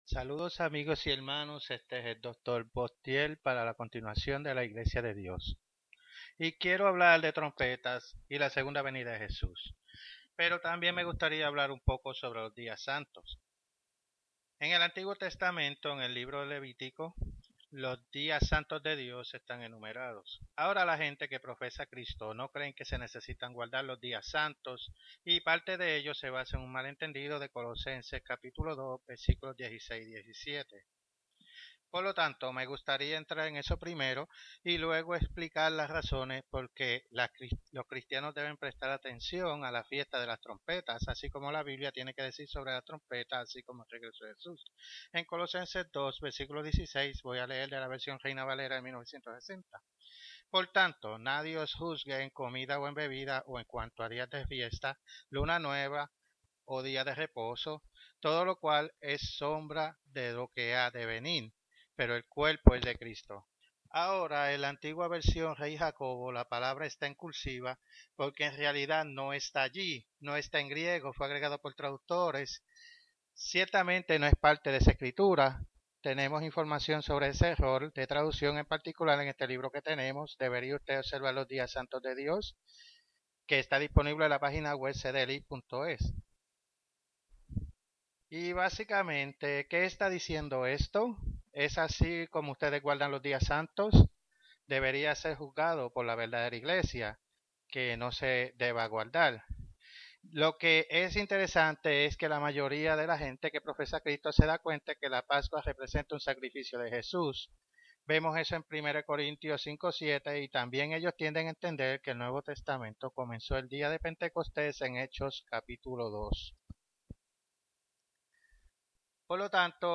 Spanish Sermons – Page 3 – Bible Prophecy News